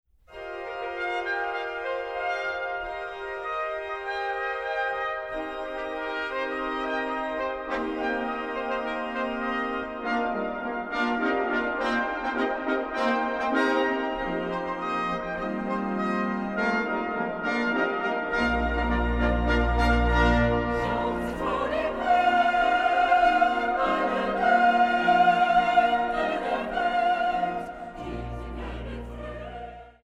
Orgel
Die Aufnahmen fanden in der Kreuzkirche in Dresden statt.